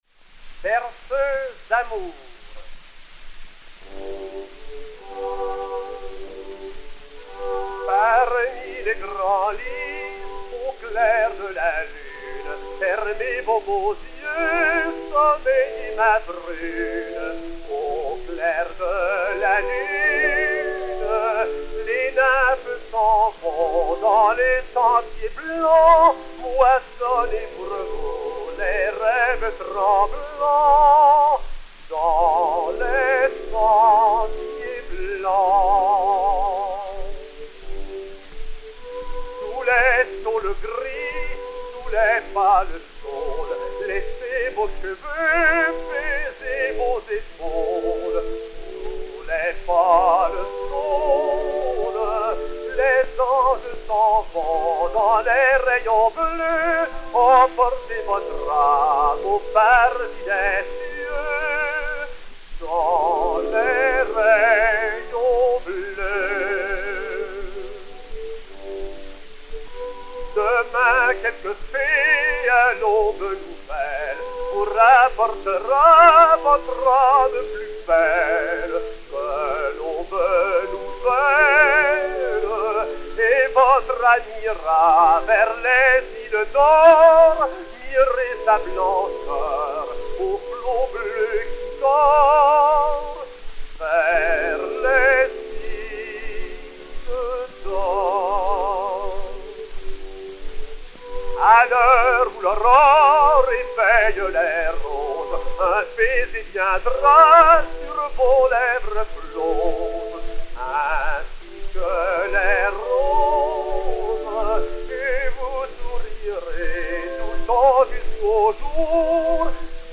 ténor français
romance (Paul Delmet)